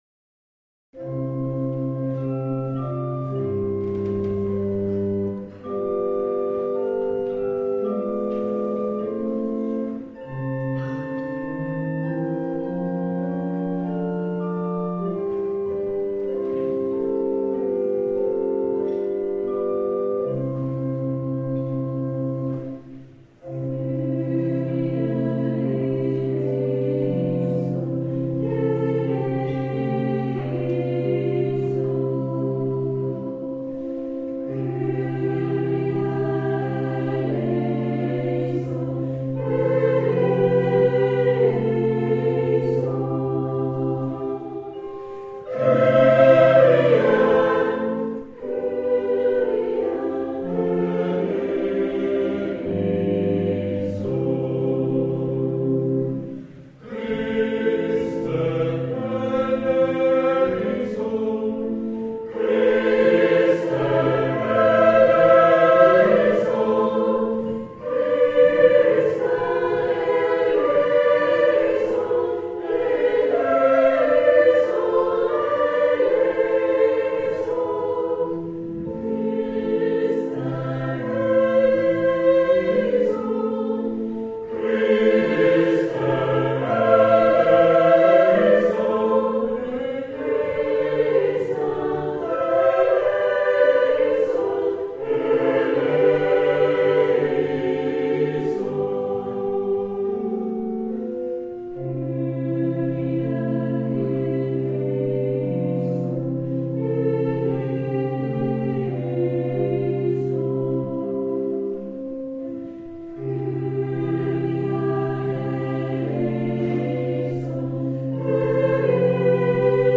Wir singen mit Orgelbegleitung die Missa breve in C von Charles Gounod und traditionelle Osterlieder - Originalmitschnitt